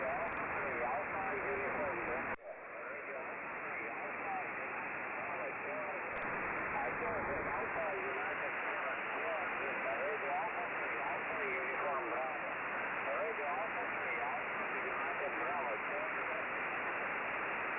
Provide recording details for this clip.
Echoes from some strong stations and from my station.